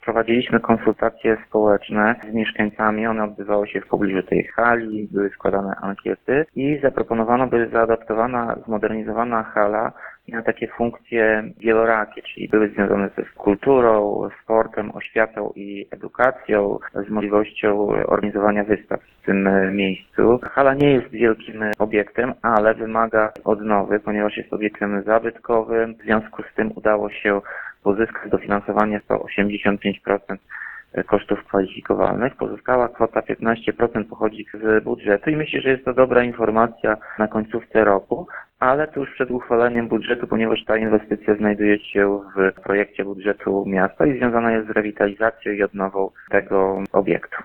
Jak mówi Tomasz Andrukiewicz, prezydent Ełku, hala może pełnić kilka funkcji.